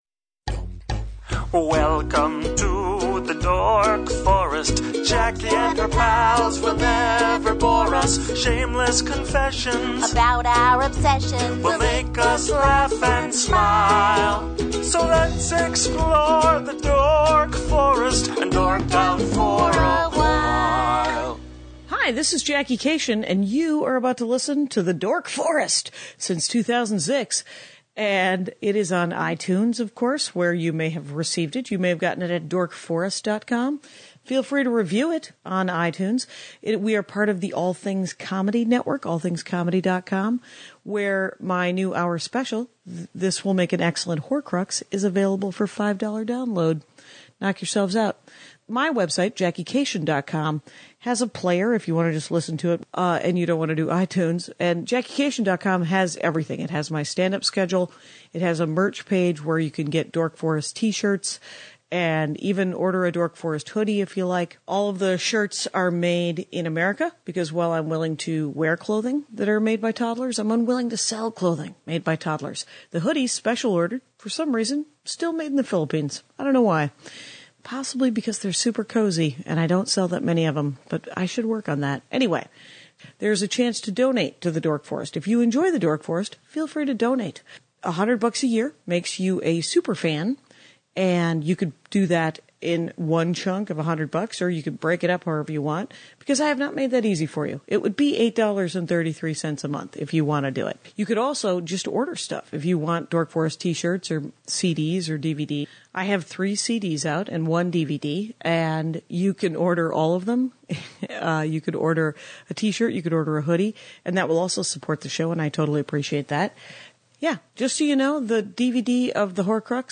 Credits: Audio leveling